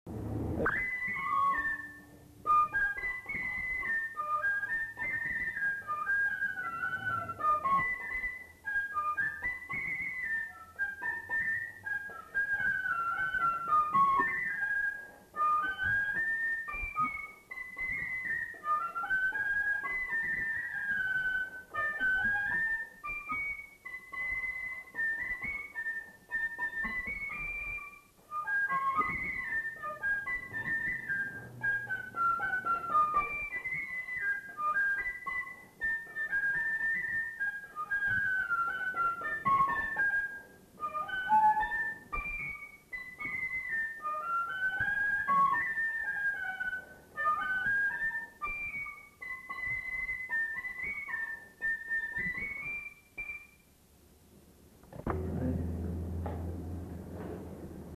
Lieu : Bazas
Genre : morceau instrumental
Instrument de musique : fifre
Danse : valse